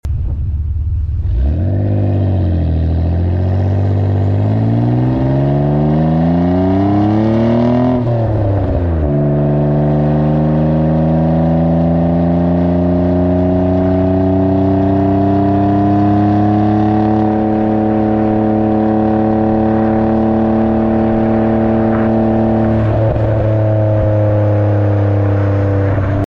3000GT VR4 Exhaust Noise sound effects free download
Mp3 Sound Effect 3000GT VR4 Exhaust Noise - Tanabe Medallion Concept G Exhaust .